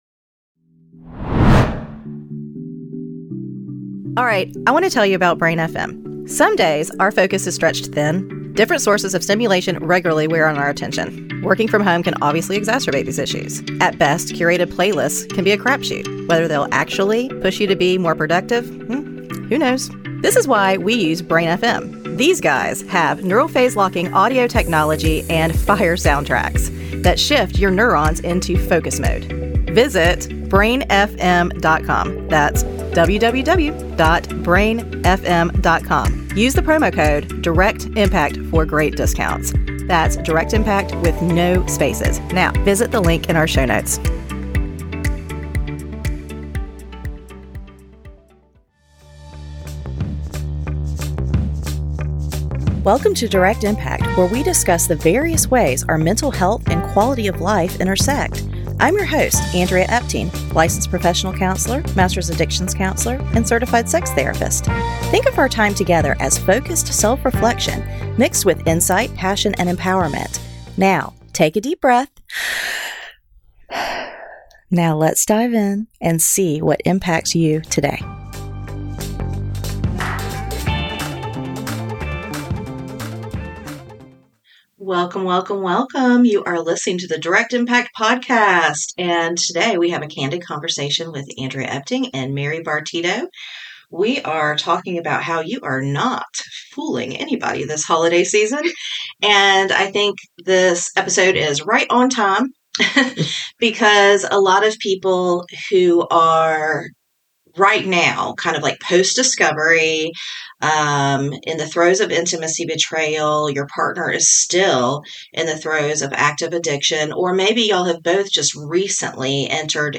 Candid Conversation